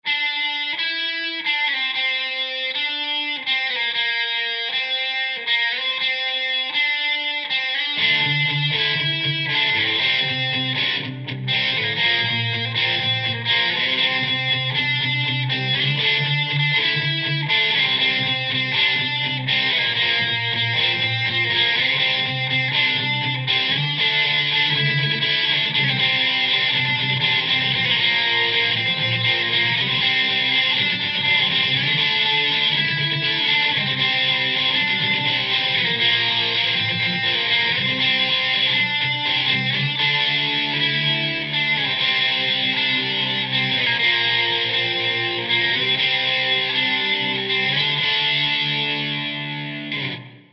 Habe gerade mal was aufgenommen, das Signal ist größtenteils unbearbeitet, ich habe nur jeweils einen leichten Chorus draufgegeben, und diesen dann nach Links bzw. Rechts gepannt... In der Vorstufe ist der Reverb etwas zu hoch gedreht, das ist mir erst später aufgefallen... Ich habe nochmal etwas mit der Position des Mikros gespielt und bin eigentlich recht zufrieden mit der Aufnahme, was sagt ihr ?